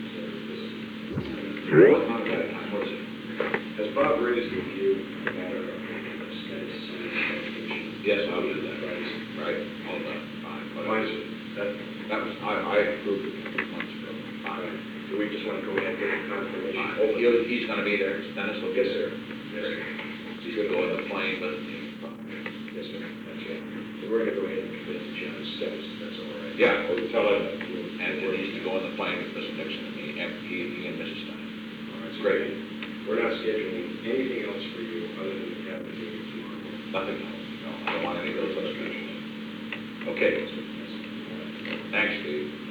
Secret White House Tapes
Conversation No. 429-10
Location: Executive Office Building